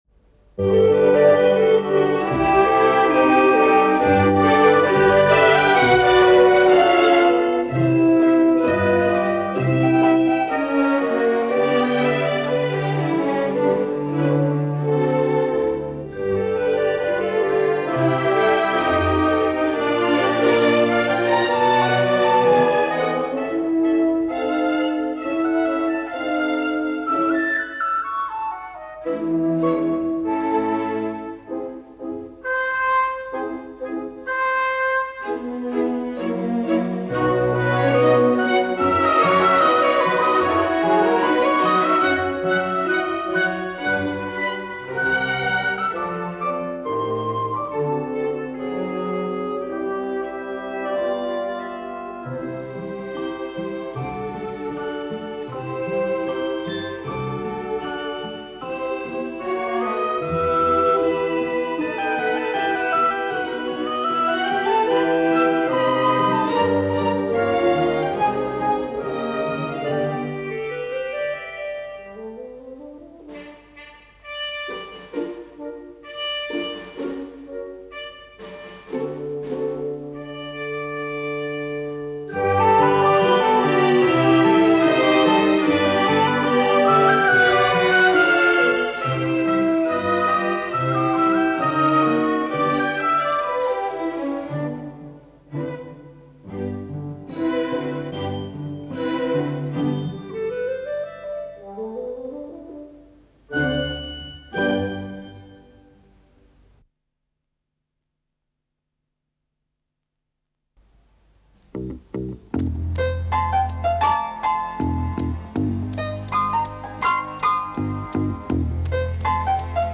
Исполнитель: 1. Симфонический оркестр 2. Инструментальный ансамбль 3. Симфонический оркестр 4. Инструментальный ансамбль 5. Эстрадный оркестр 6. -